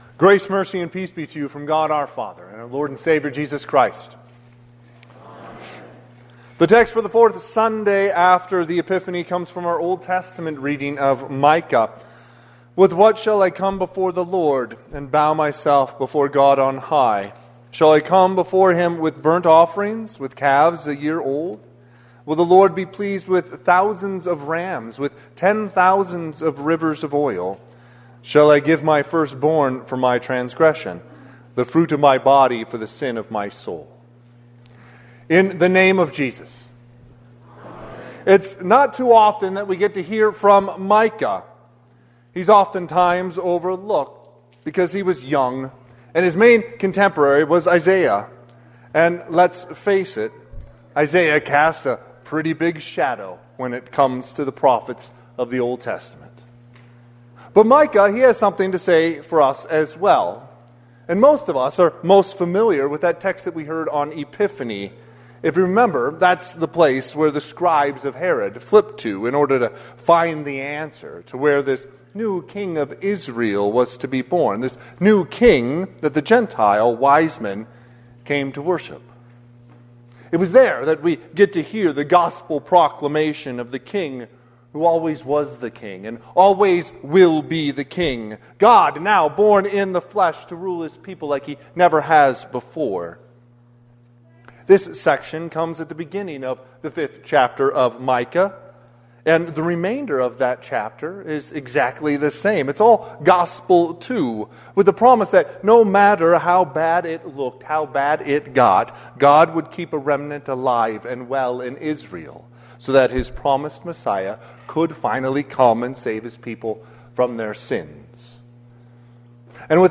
Sermon - 2/1/2026 - Wheat Ridge Evangelical Lutheran Church, Wheat Ridge, Colorado
Fourth Sunday after the Epiphany
Sermon_Feb1_2026.mp3